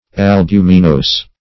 Albuminose \Al*bu"mi*nose`\, n. (Chem.)